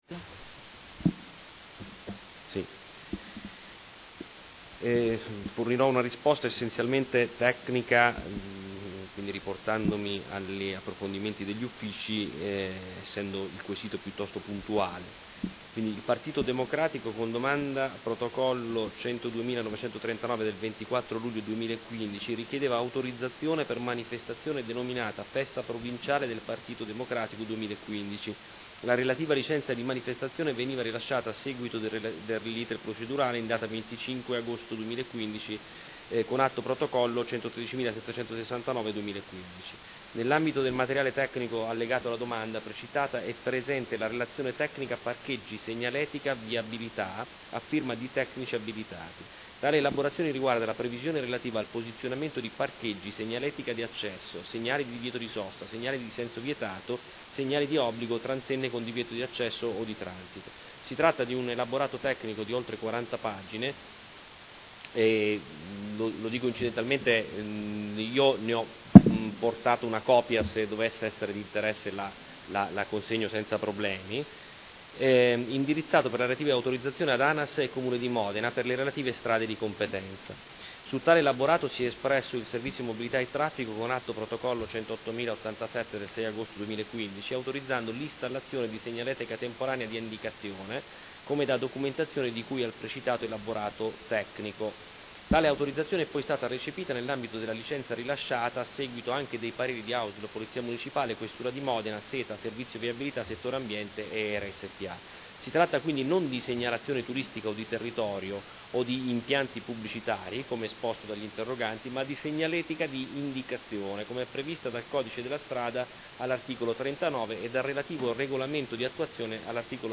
Seduta del 15 ottobre. Interrogazione del Gruppo Consiliare Movimento 5 stelle avente per oggetto: Cartelli di Parcheggio in tangenziale e affluenti.